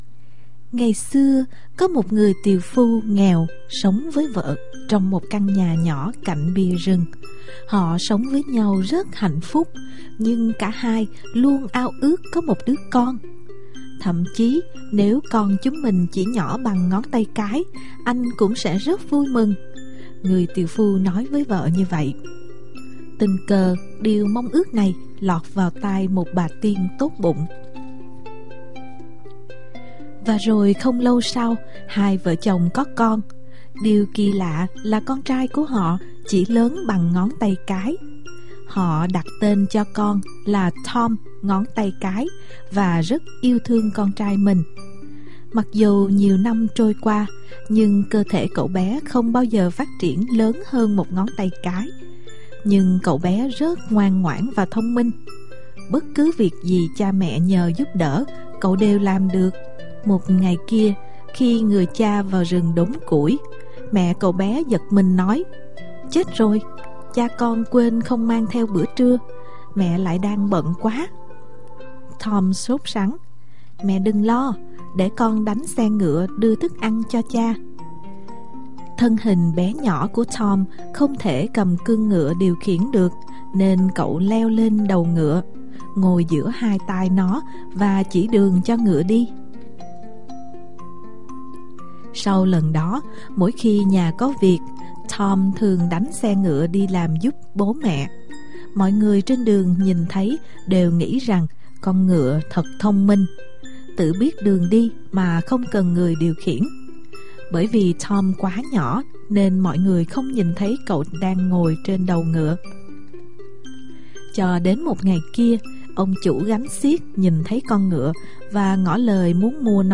Sách nói | Chú bé tí hon